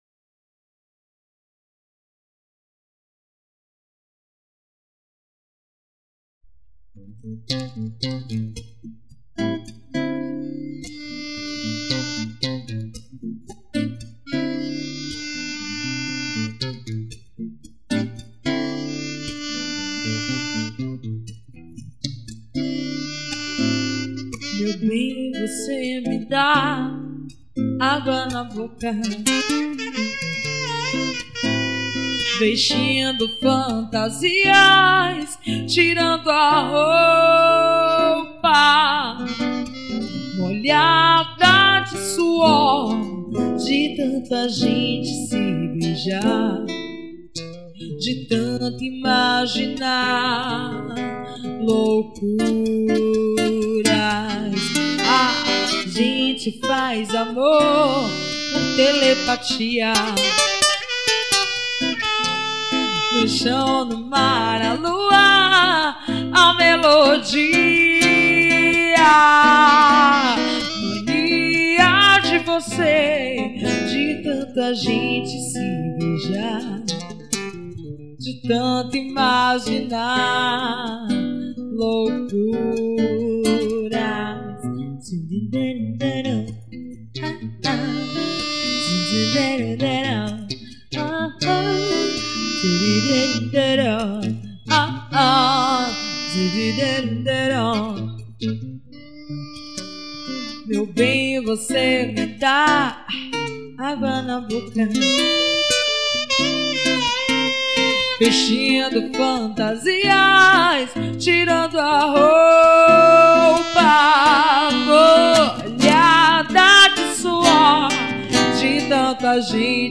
mpb.